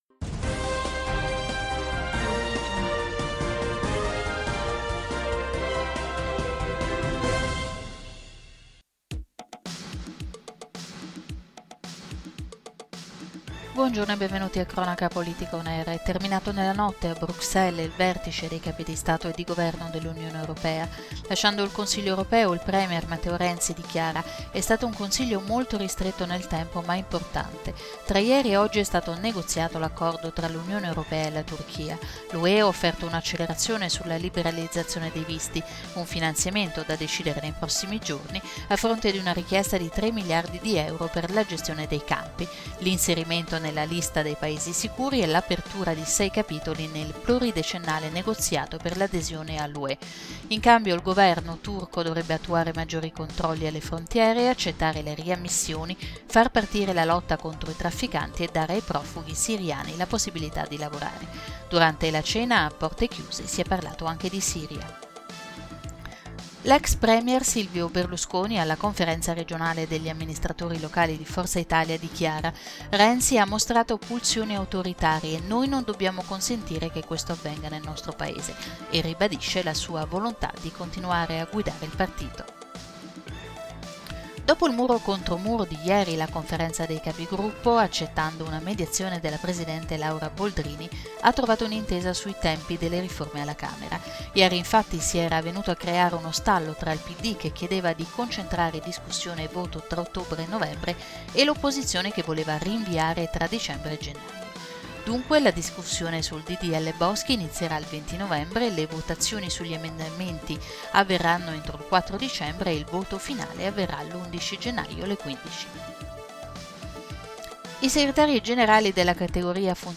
Notiziario 16/10/2015 - Cronaca politica